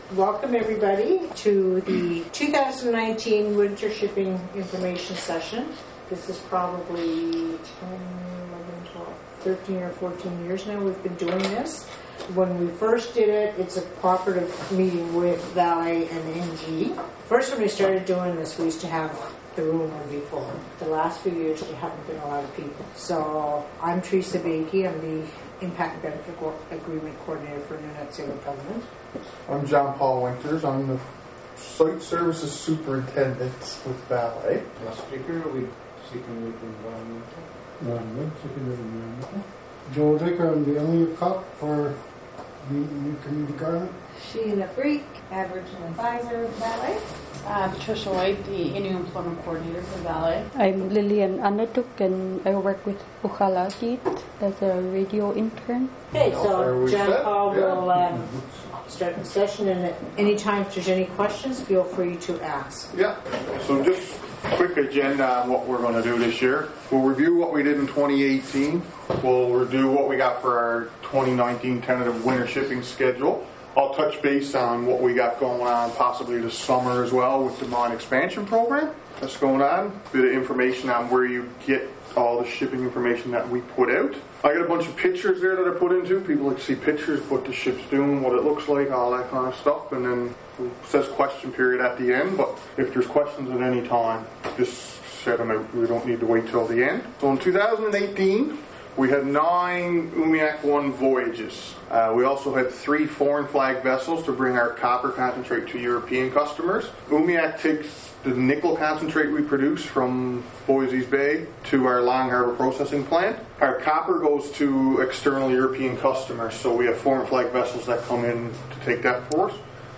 The first one was held in Nain Monday evening, January 7, 2019, at the Nunatsiavut Government Administration Building boardroom.
OK Radio was at the NG building here in Nain to record the meeting that was held Monday evening.